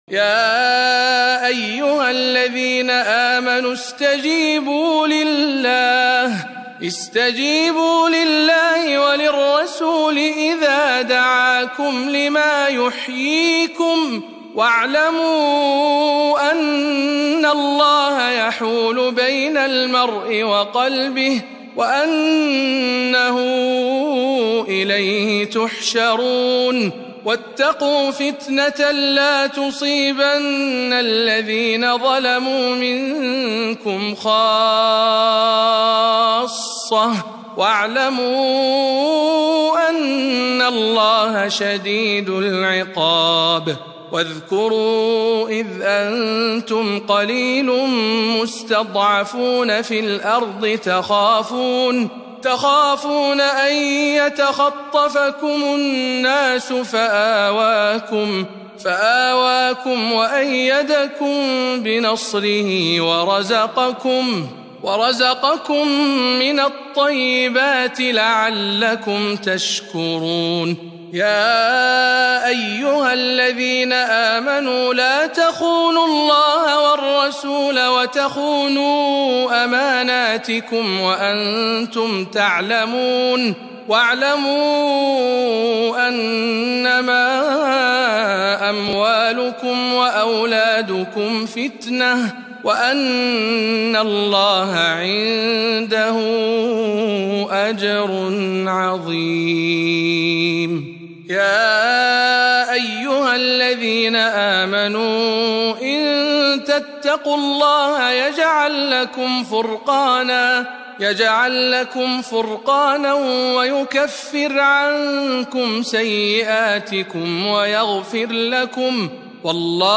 تلاوة مميزة من سورة الأنفال